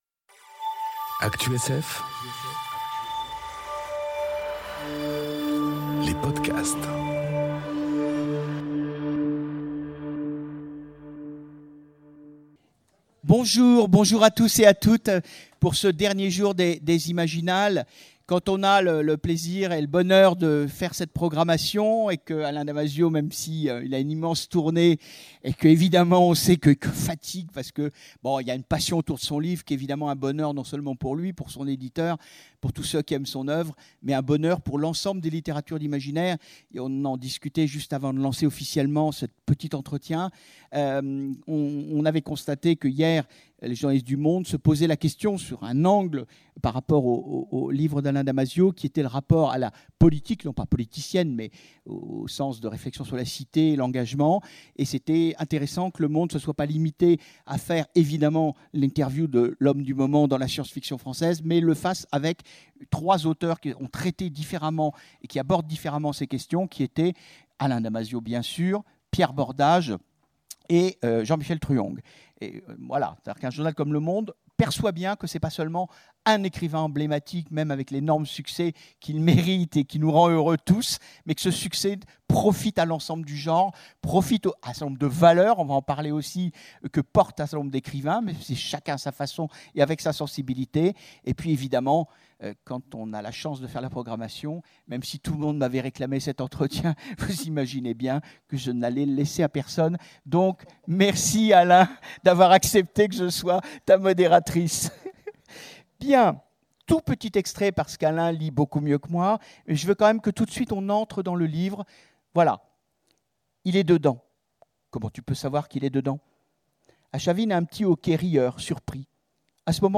Imaginales 2019 : Le Grand Entretien avec Alain Damasio